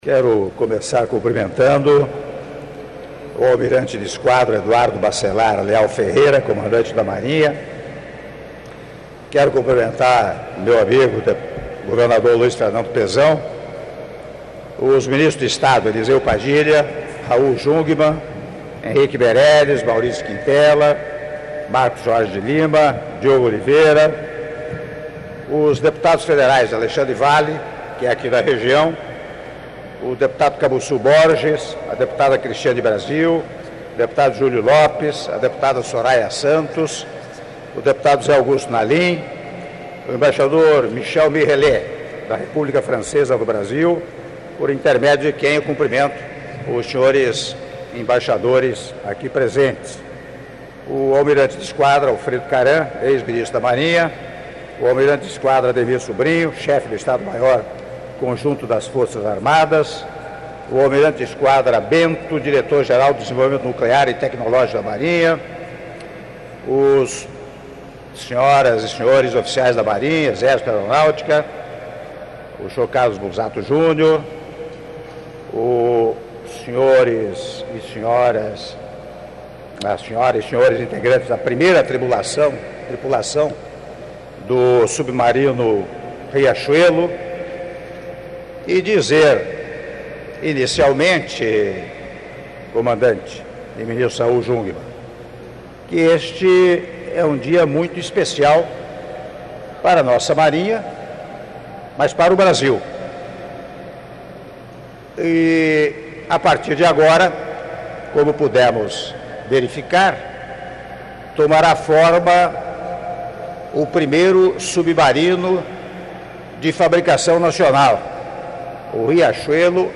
Áudio do discurso do Presidente da República, Michel Temer, durante cerimônia de Início da Integração dos Submarinos Classe Riachuelo - Itaguaí/RJ - (07min55s)